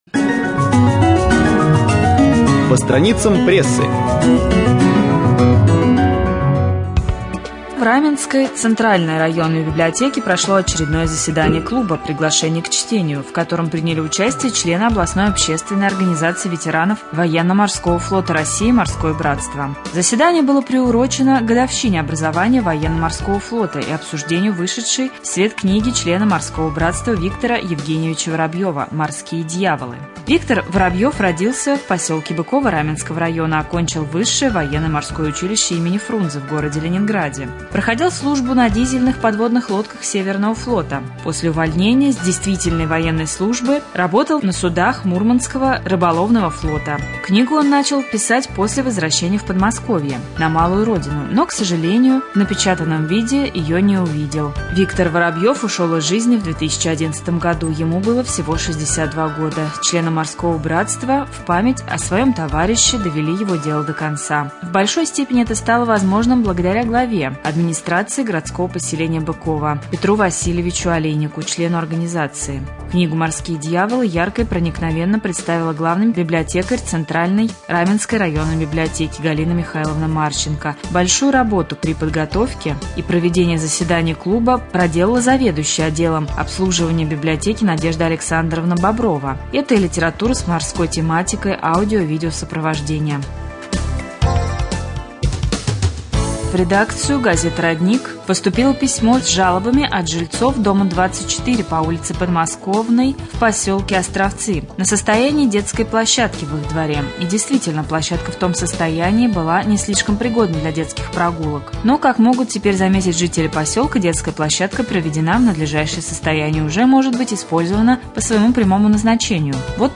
18.11.2014г. в эфире Раменского радио - РамМедиа - Раменский муниципальный округ - Раменское
2.Новости.mp3